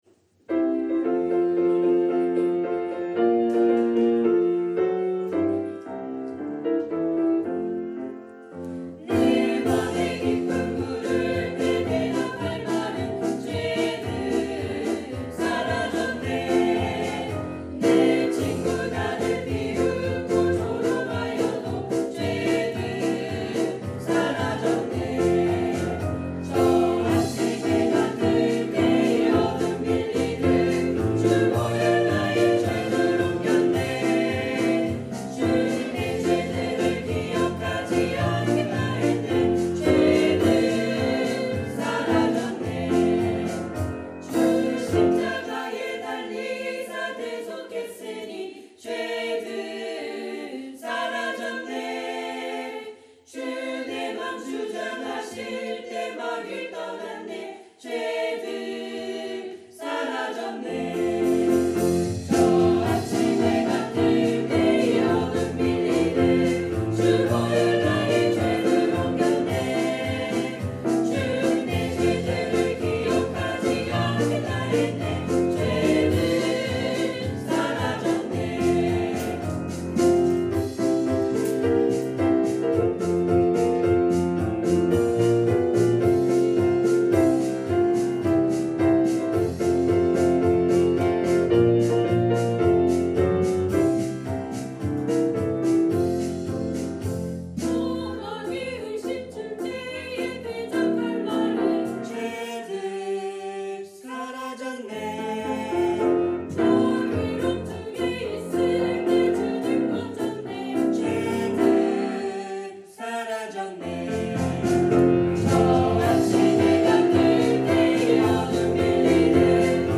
psalmchoir